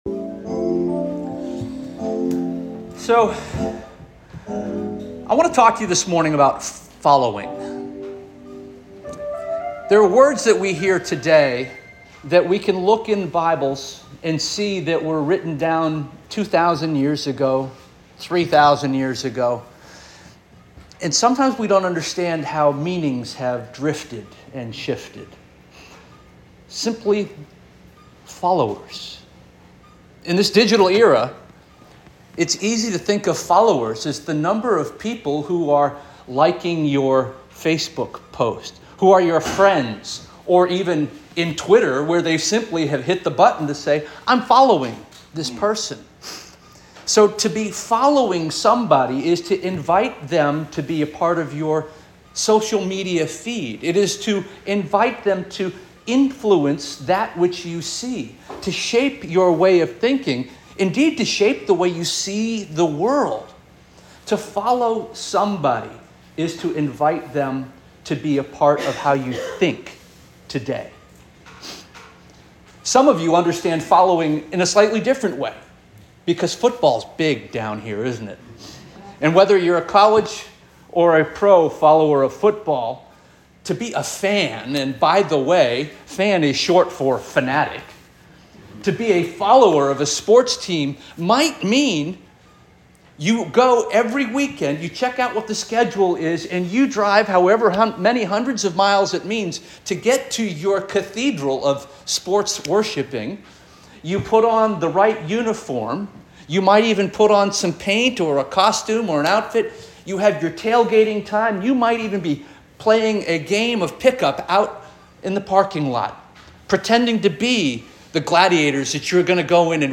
June 30 2024 Sermon - First Union African Baptist Church